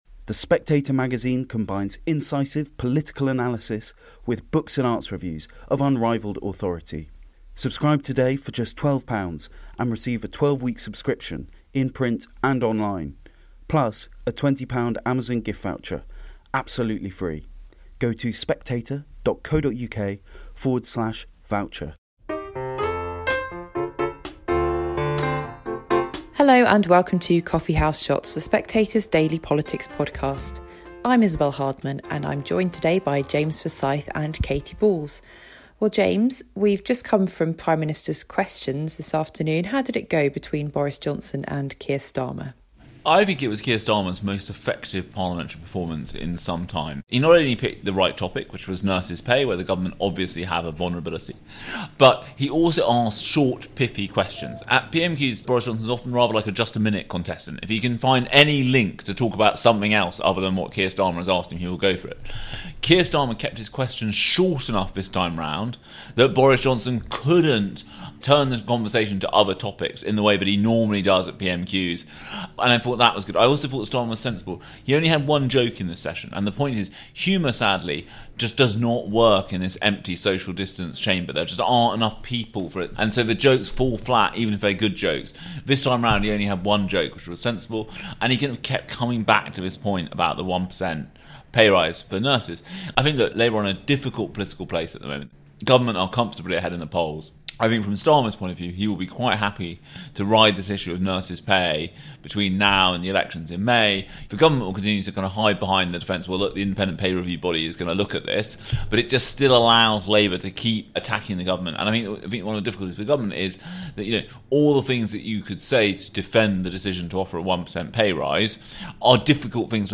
News, Politics, Government, Daily News